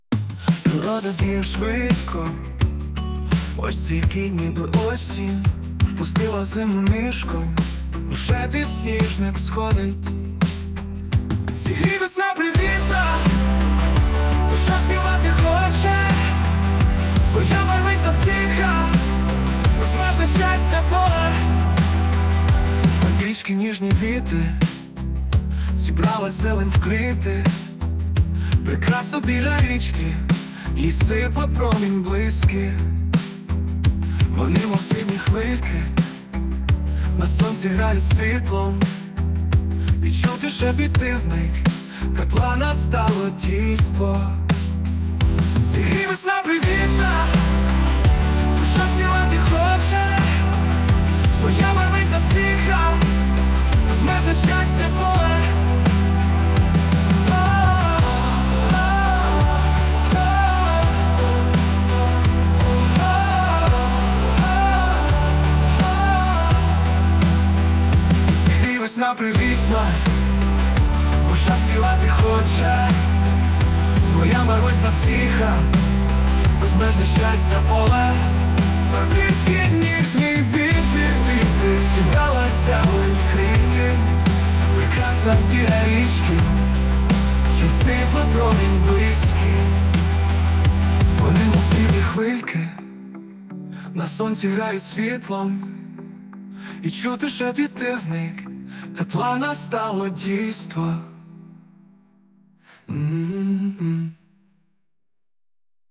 Музична композиція створена за допомогою ШІ
Легка, приємна пісня весняній природі. 16 22 give_rose